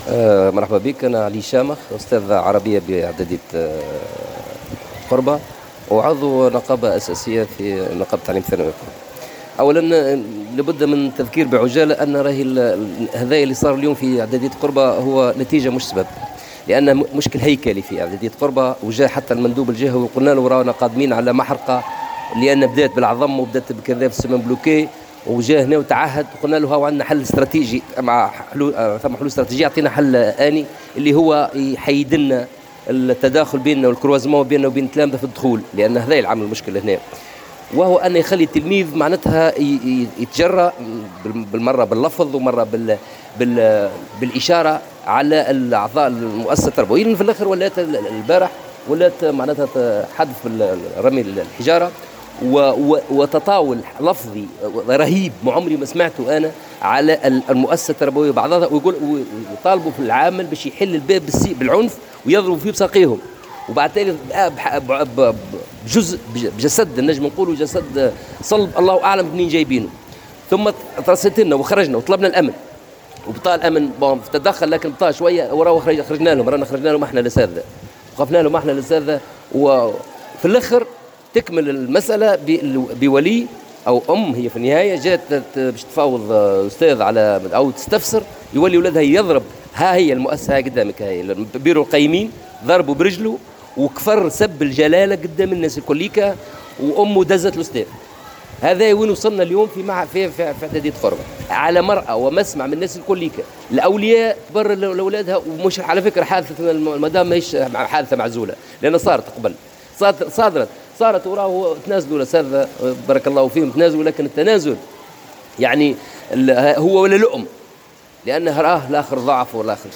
Nabeul : Suspension des cours dans une école après l’agression des enseignants (Déclaration)